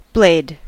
Ääntäminen
US : IPA : [ˈbleɪd]